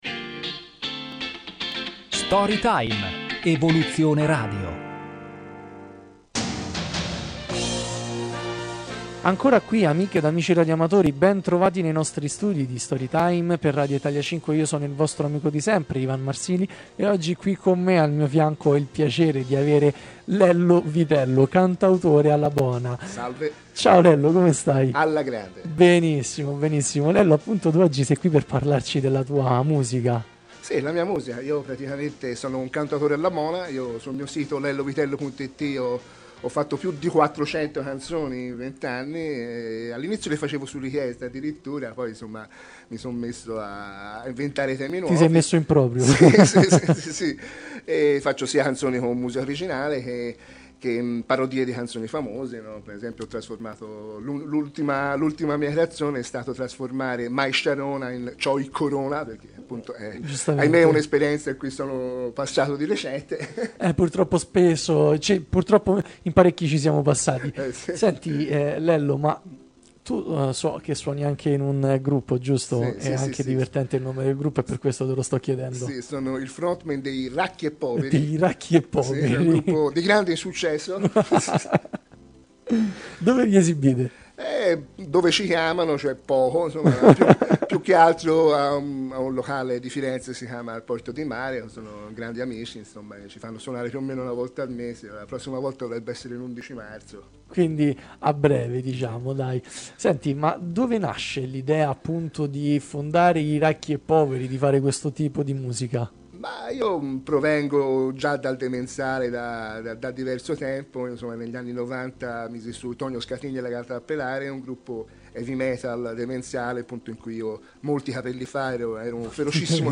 Intervista a Story time (Radio Italia 5)
Sono stato intervistato nel corso del programma radiofonico